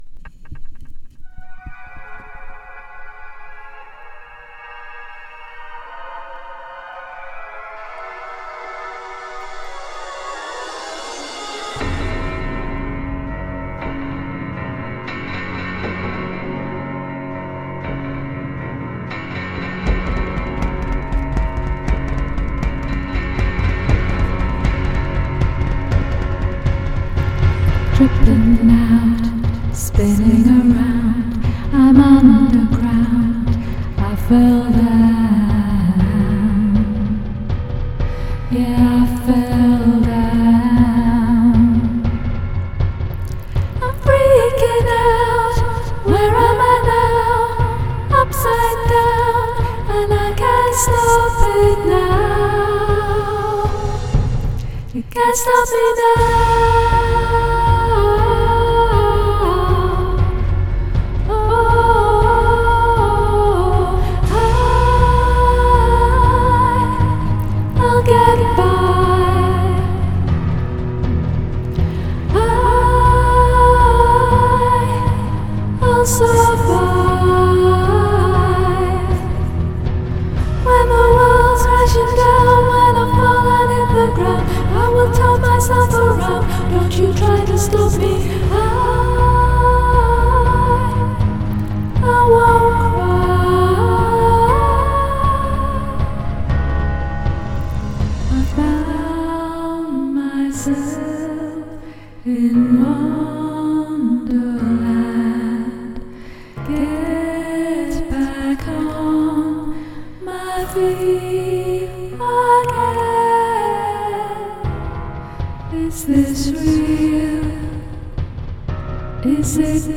My weedy-voiced, folksy